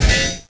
cannonhit.wav